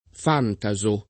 [ f # nta @ o ]